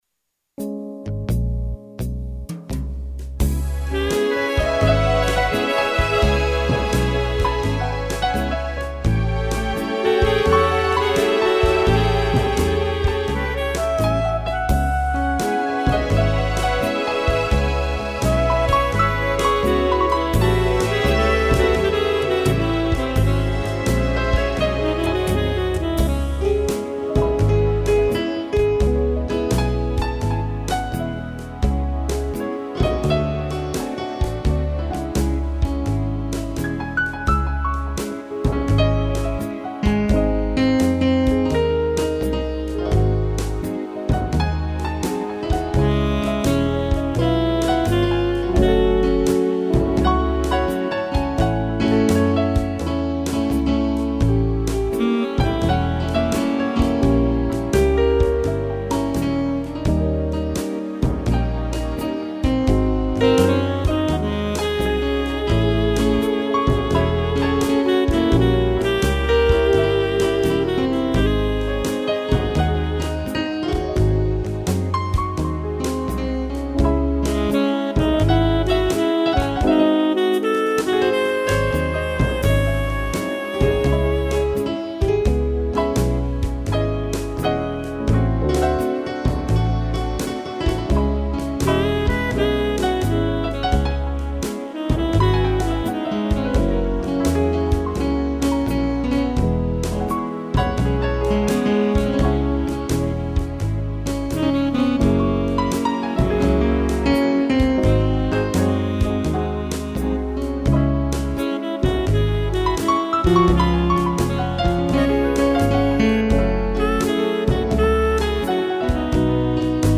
piano e sax
instrumental